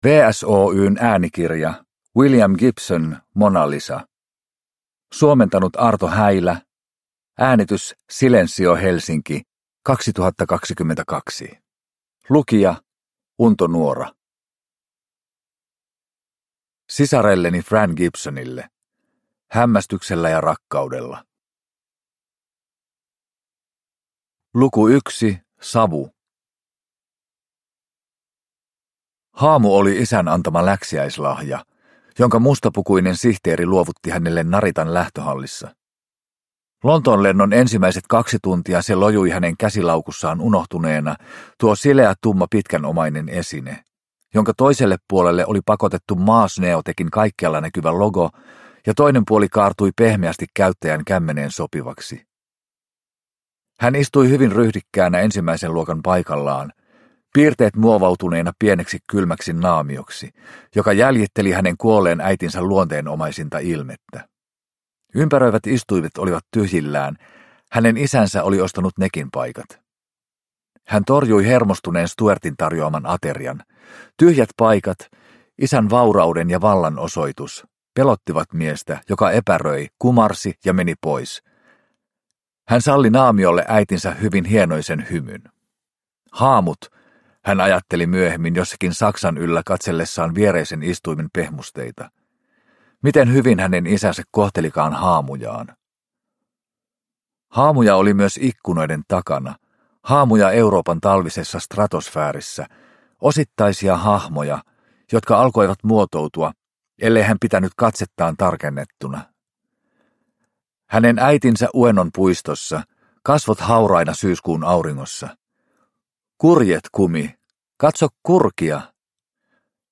Mona Lisa – Ljudbok – Laddas ner